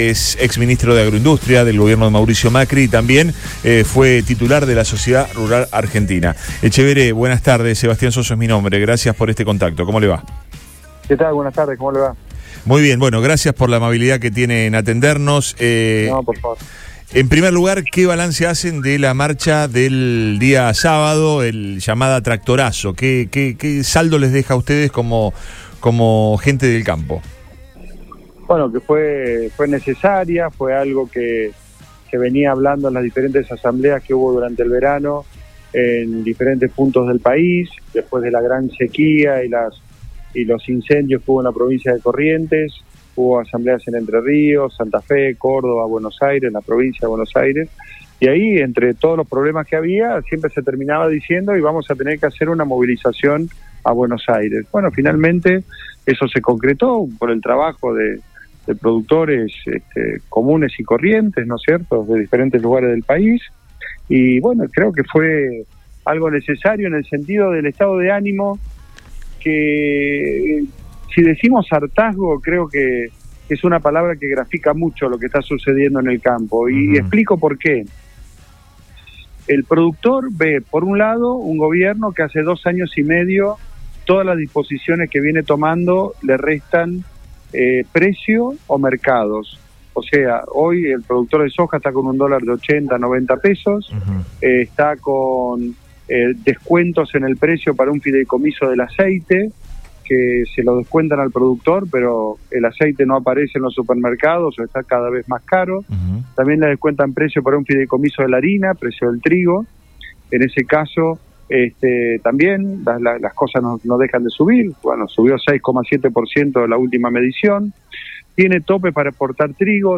En este contexto Luis Miguel Etchevehere, ex Ministro de Agroindustria del gobierno de Mauricio Macri, habló en Radio Boing sobre el impacto de las medidas y la necesidad de visibilizar el reclamo del sector.